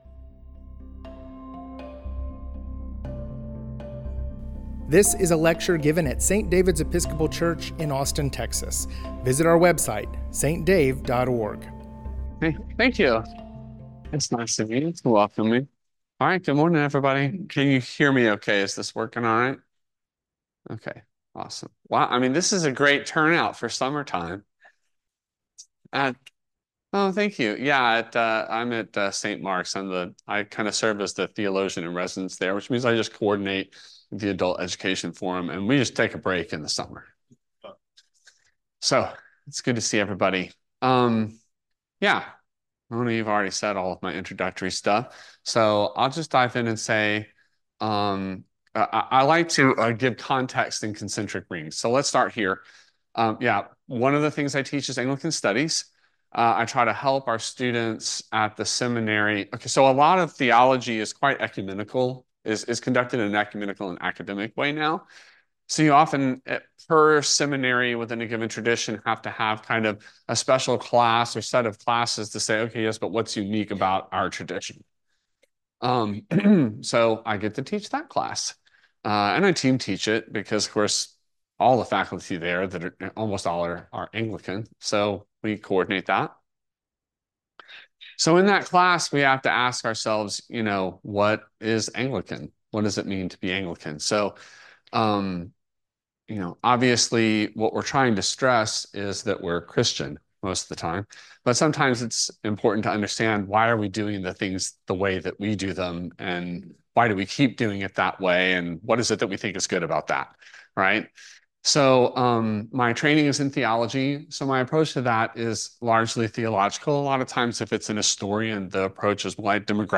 presents part II of his lecture exploring the lives and writings of literary figures such as C.S. Lewis, T.S. Eliot, Christina Rossetti, and Madeline L'Engle.